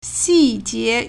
细节 (細節) xìjié
xi4jie2.mp3